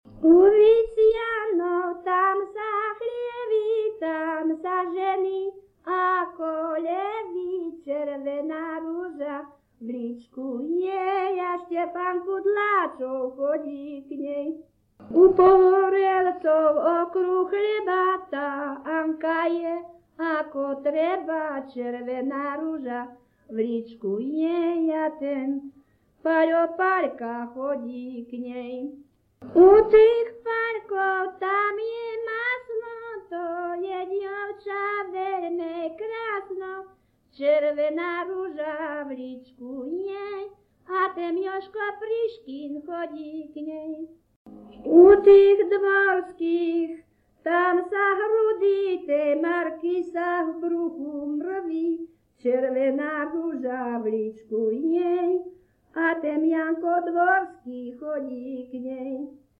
Descripton sólo ženský spev bez hudobného sprievodu
Place of capture Litava
Key words ľudová pieseň